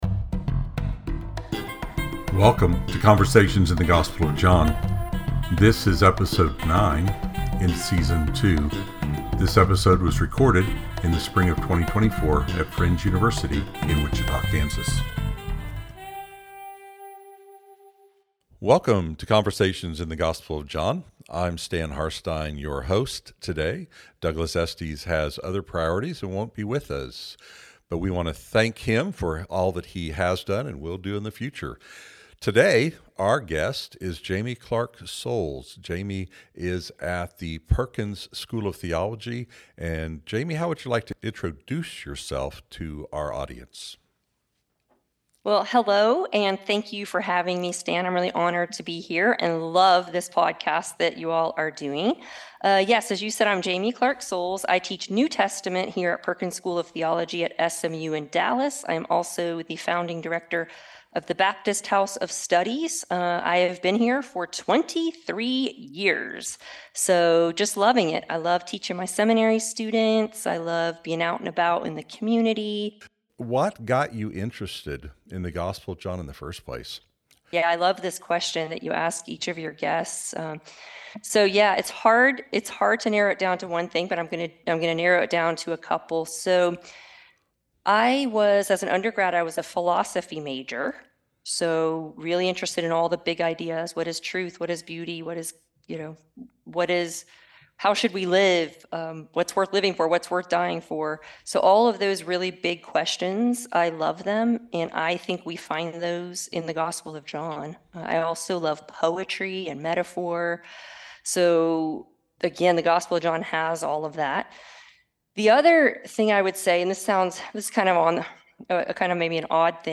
Episode 9 | Podcasts | Conversations in the Gospel of John